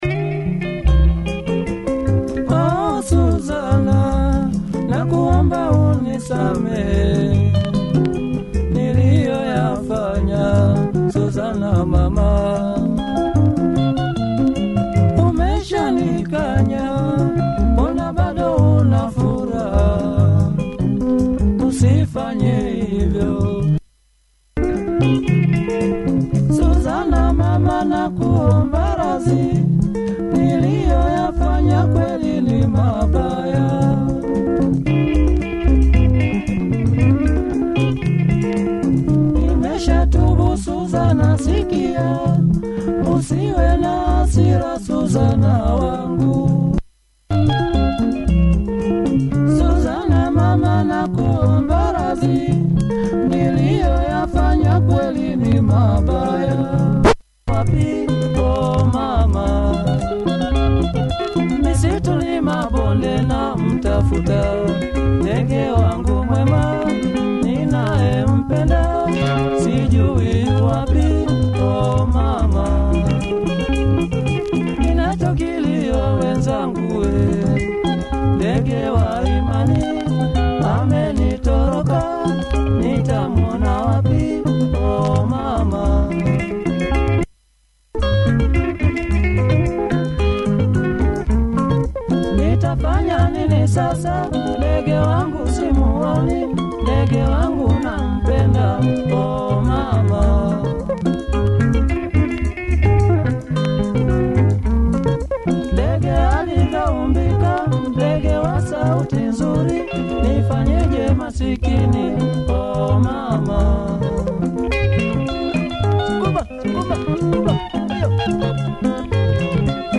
Clean copy! https